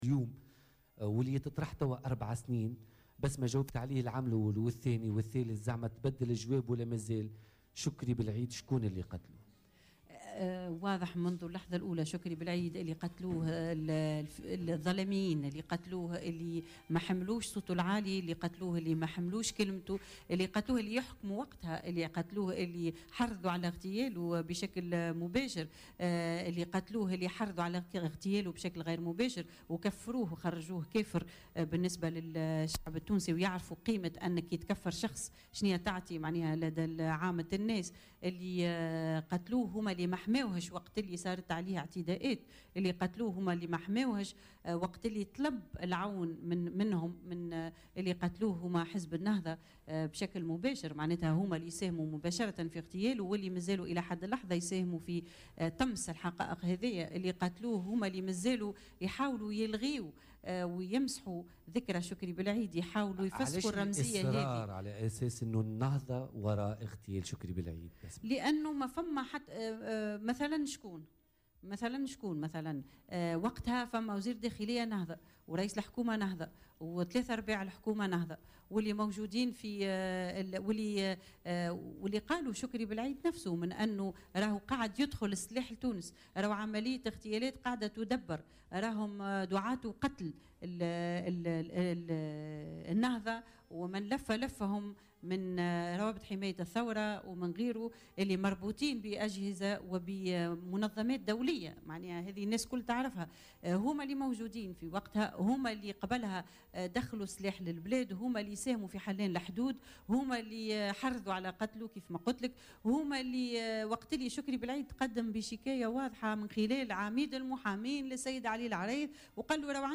قالت بسمة بلعيد ضيفة حلقة بوليتيكا الخاصة بذكرى اغتيال شكري بلعيد اليوم الإثنين 6 فيفري 2017 أن النهضة كانت وراء اغتياله وهي اليوم تواصل لعب دورها في طمس الحقيقة.